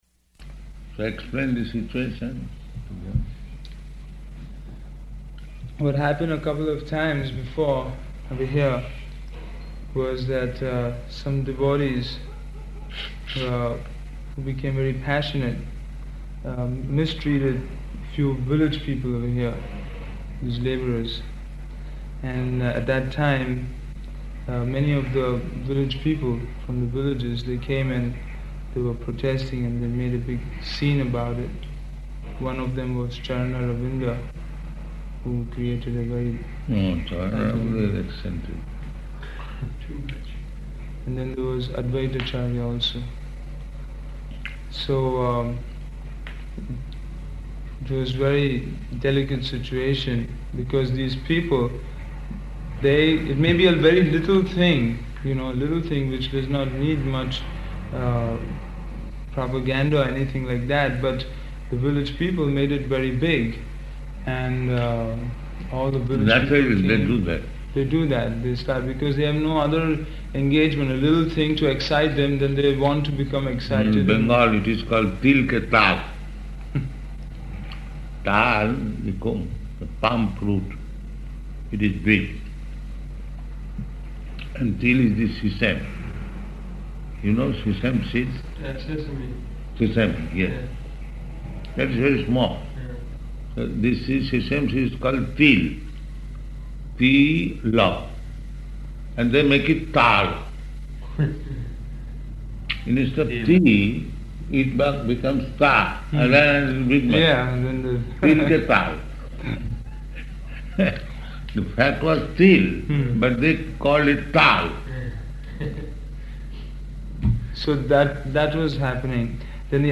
Room Conversation
Room Conversation --:-- --:-- Type: Conversation Dated: December 12th 1976 Location: Hyderabad Audio file: 761212R1.HYD.mp3 Prabhupāda: So explain the situation to them.